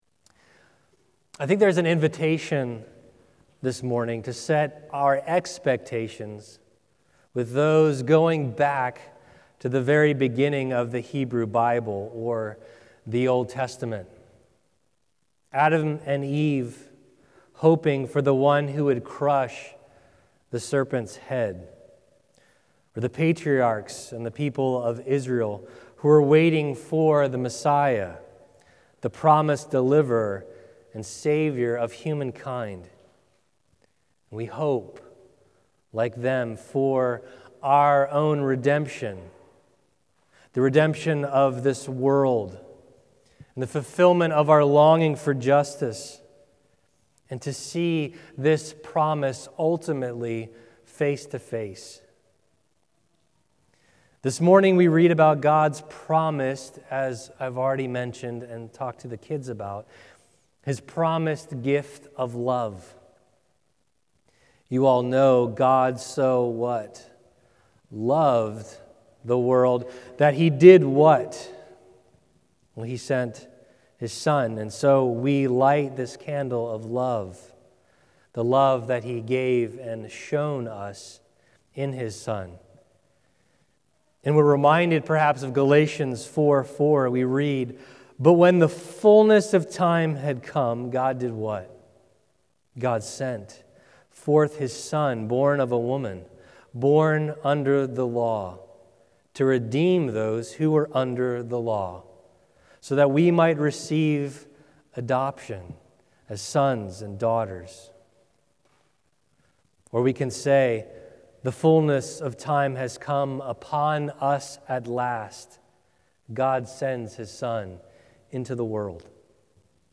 Advent 2023 Guest Speaker December 10, 2023 View all Sermons in Series